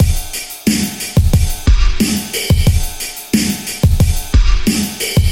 所有时间的节拍
描述：嘻哈鼓
Tag: 90 bpm Hip Hop Loops Drum Loops 917.81 KB wav Key : Unknown